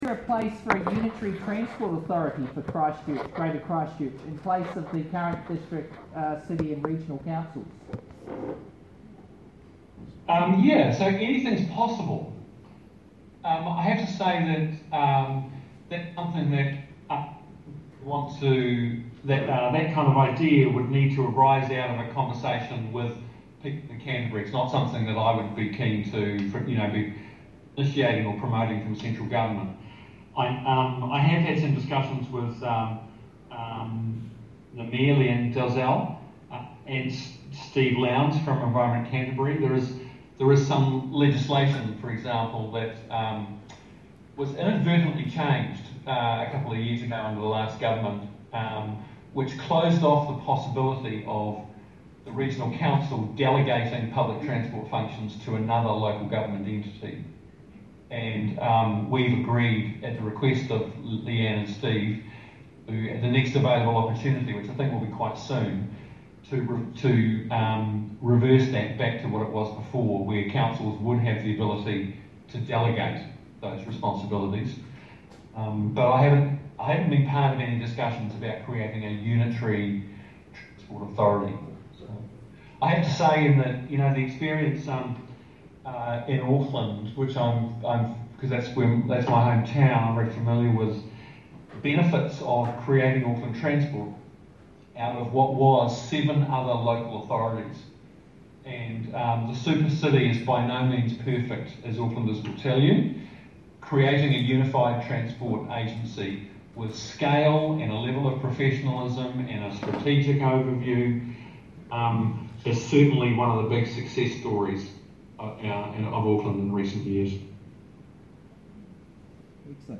[Editor’s Note, Phil Twyford was asked this question at our workshop two weeks ago and gave this answer…]